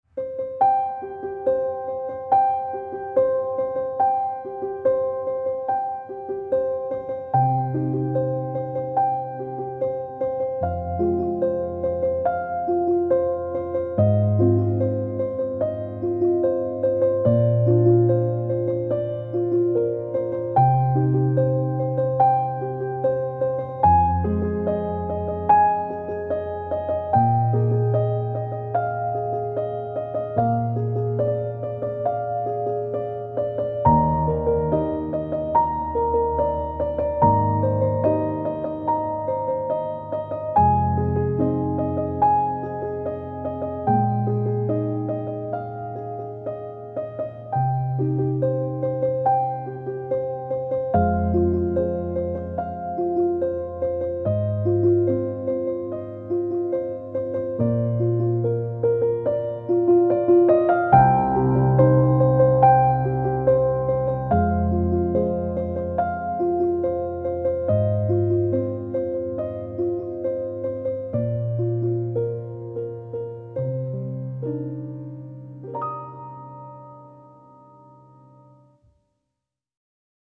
- Атмосфера: Расслабляющая, медитативная.
- Темп: Медленный, размеренный.
- Настроение: Спокойное, умиротворяющее, мечтательное.